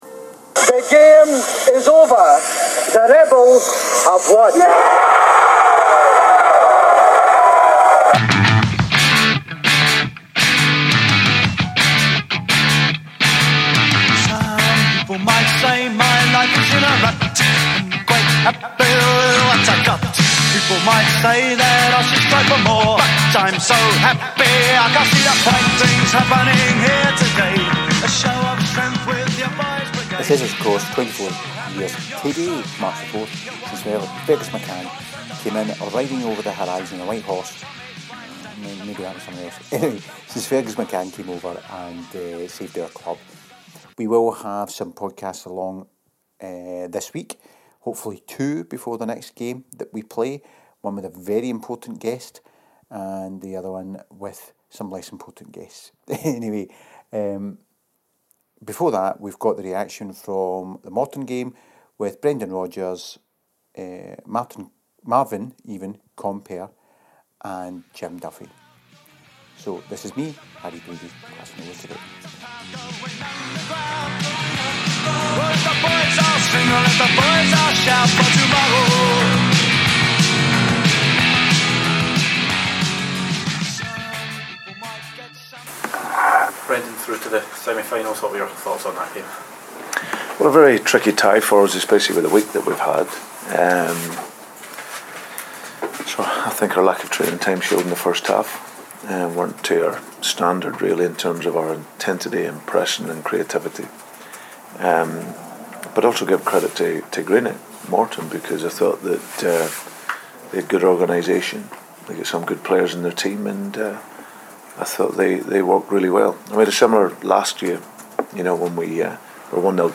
Following the tie Compper, Brendan and Jim Duffy were the people at the post game press conference (Marvin being honest enough to admit he was rusty for the first part of the game).